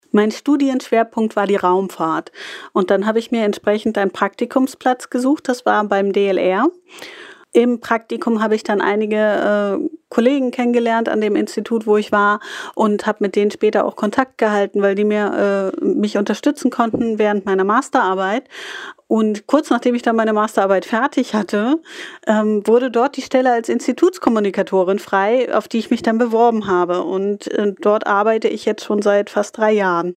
Sprachnachricht